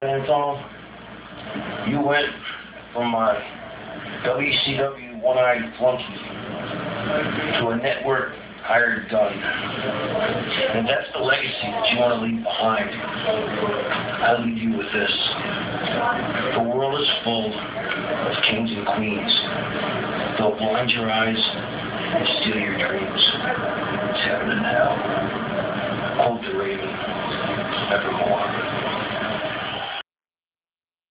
the sound section: themes, quotes, and speeches available for download
- This file comes from the ECW on TNN - [11.5.99.] Raven talks about how he hates the fact that Dreamer has let The Sandman join their family for the November To Remember PPV and how much he hates the Impact Players.